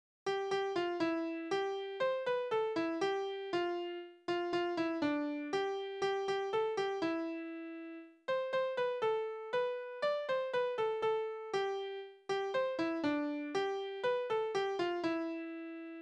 Balladen: Räuber und Bruder
Tonart: C-Dur
Taktart: 2/4
Tonumfang: Oktave
Besetzung: vokal